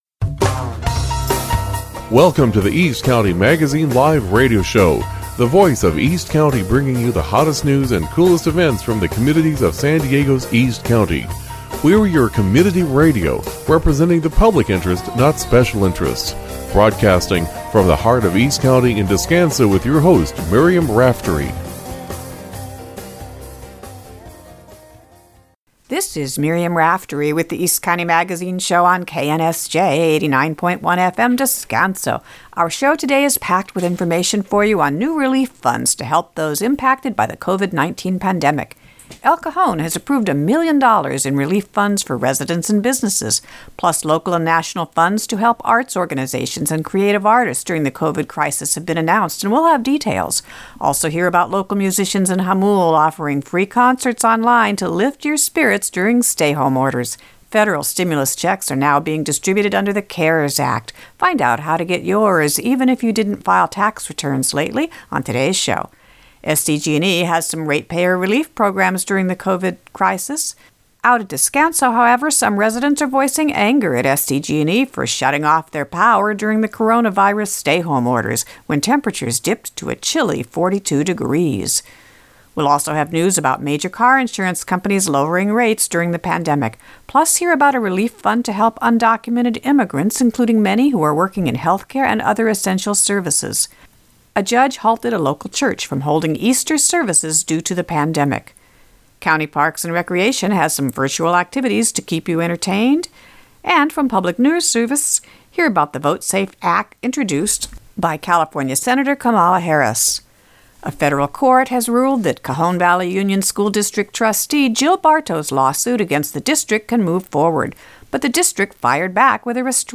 April 17, 2020 (San Diego's East County) -- Our award-winning radio show on KNSJ 89.1 FM has been providing critical community updates on relief funds to help local residences, impacts of the COVID-19 pandemic on communities in San Diego's inland region, as well as the latest updates on COVID-19 and